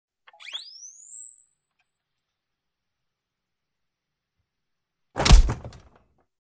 Долгая зарядка дефибриллятора и разряд